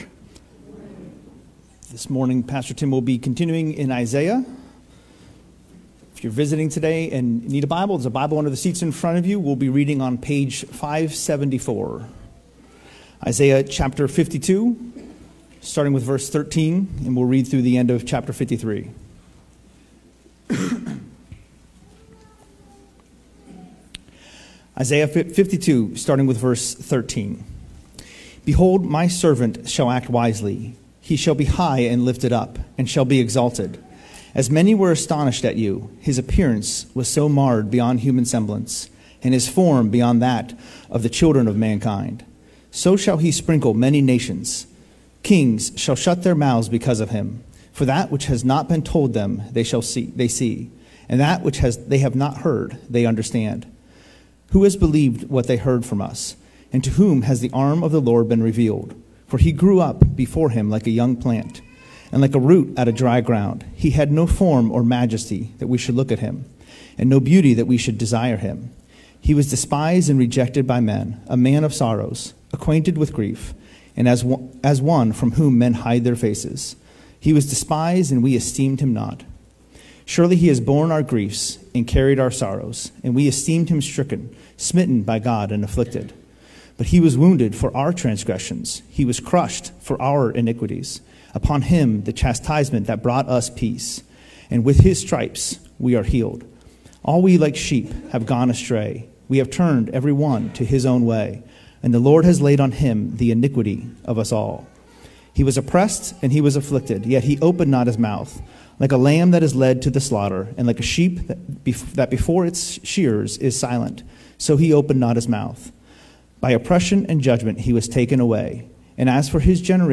Sermons | Calvary Baptist Church